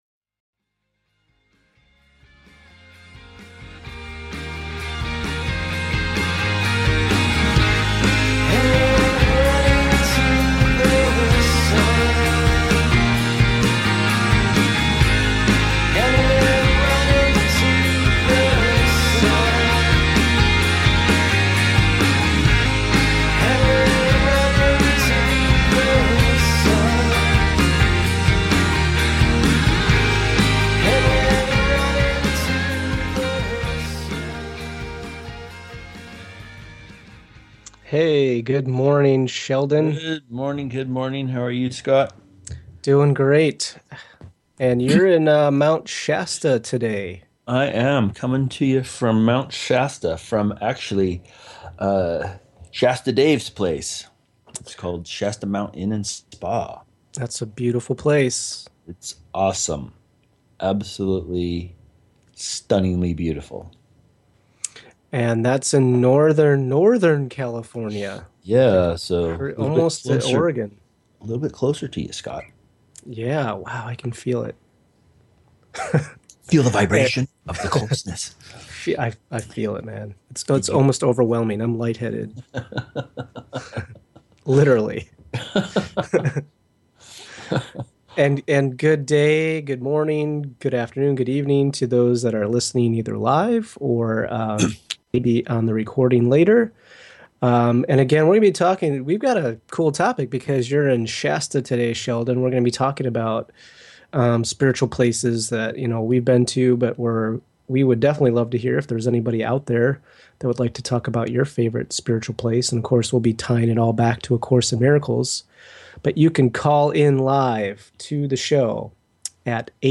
Of Course Radio is a lively broadcast focused on the messages within ‘A Course in Miracles’ as well as in-depth explorations into how we live forgiveness in our daily lives and remember our connection with God/Source.